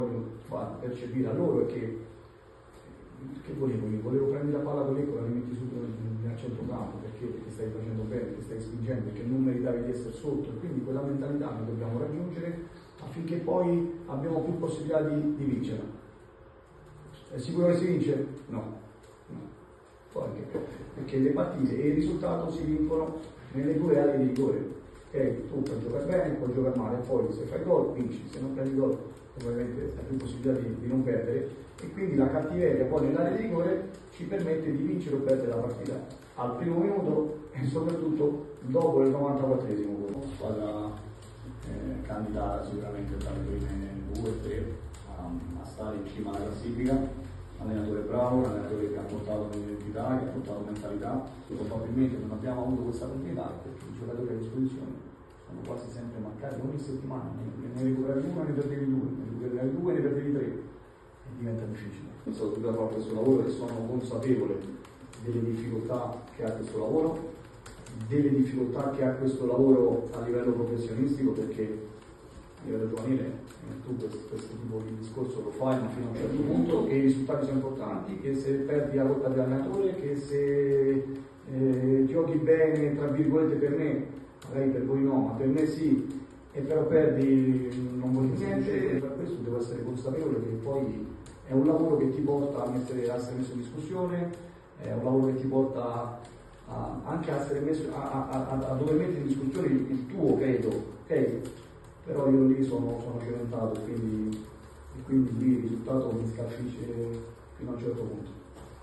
Un estratto dalla conferenza di Alberto Aquilani alla vigilia di Venezia-Pisa.